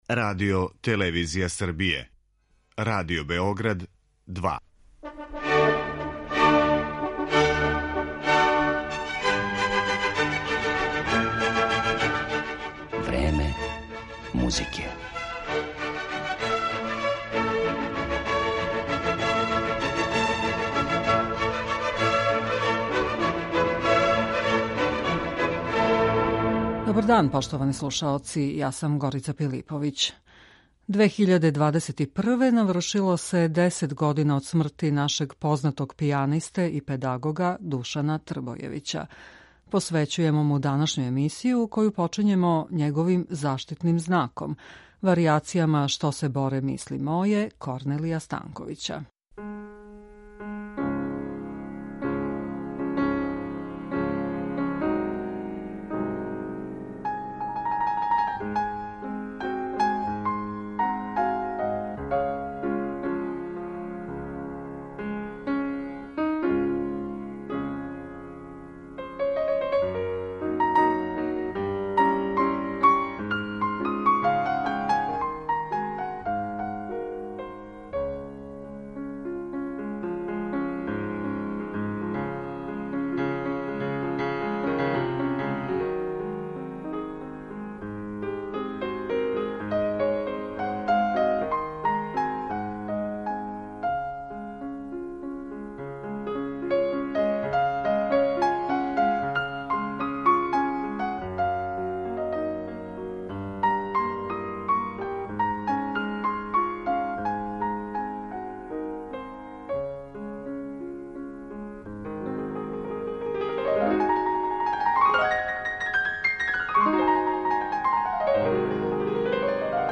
као и одабране Трбојевићеве снимке из опуса Корнелија Станковића, Василија Мокрањца, Лудвига ван Бетовена, Даријуса Мијоа и Марка Тајчевића.